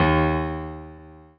piano-ff-19.wav